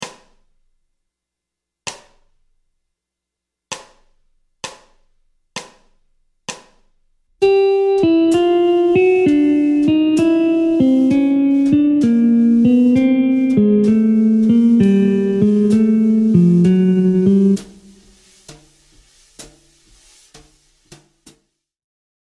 Gamme mineure mélodique ( I – mode mineur Majeur)
Descente de gamme
Gamme-bop-desc-Pos-42-C-min-mel.mp3